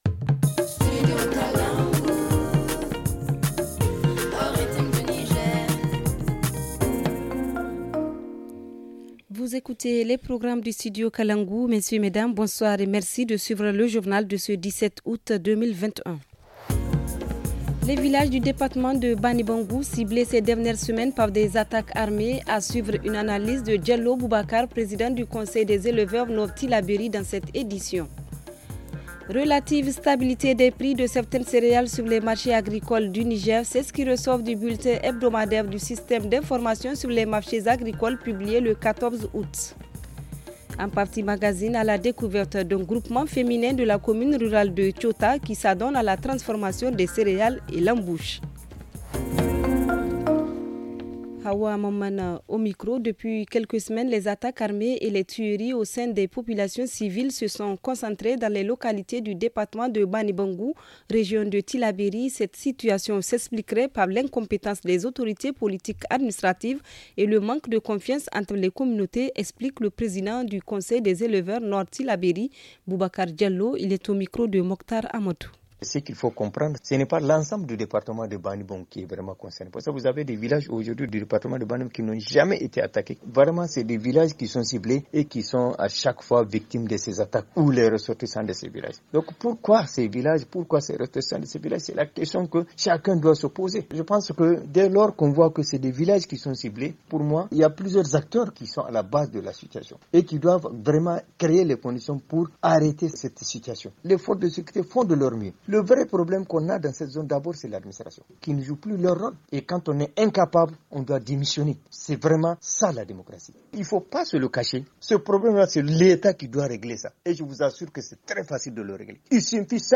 Le journal du 17 août 2021 - Studio Kalangou - Au rythme du Niger